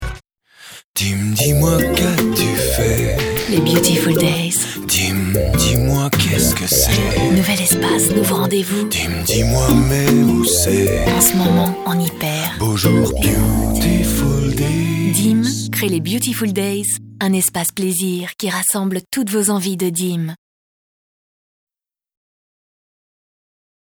Doux, Elegant, Murmuré
Publicité radio
Sensuel